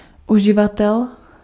Ääntäminen
US : IPA : [ˈju.zɚ]